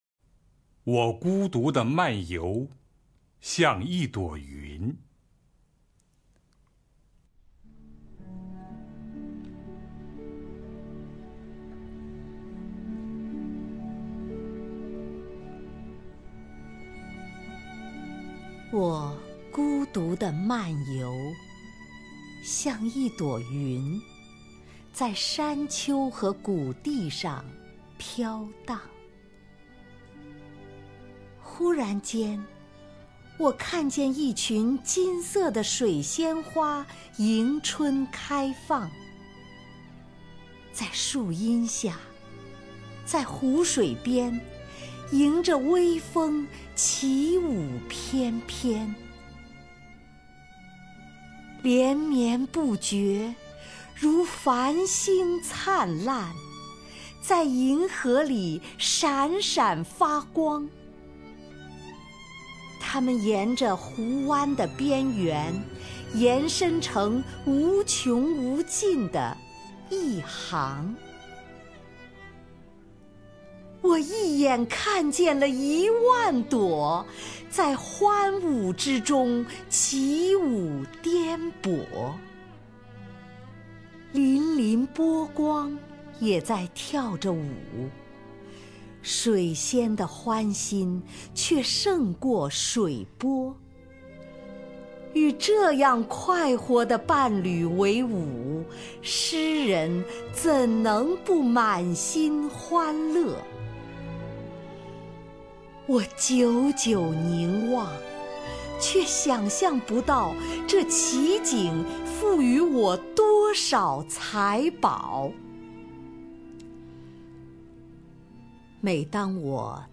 丁建华朗诵：《我孤独地漫游，像一朵云》(（英）威廉·华兹华斯)
WoGuDuDeManYou,XiangYiDuoYun_WilliamWordsworth(DingJianHua).mp3